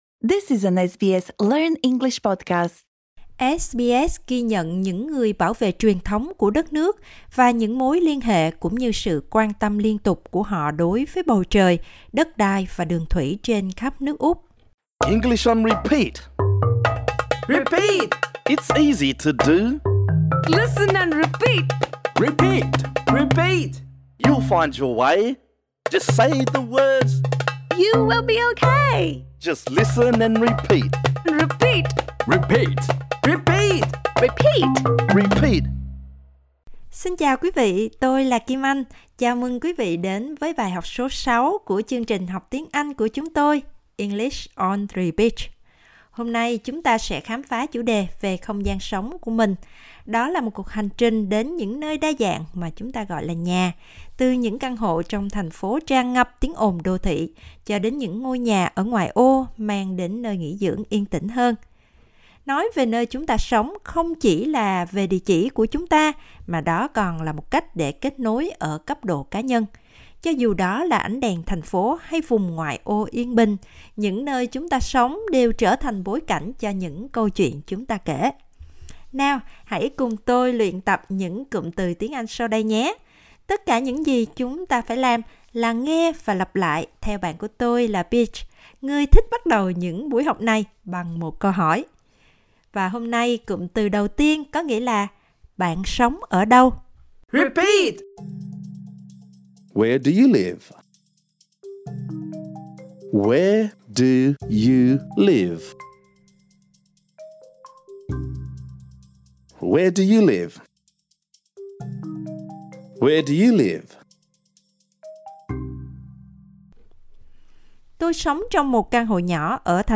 Bài học này dành cho những người học ở trình độ dễ.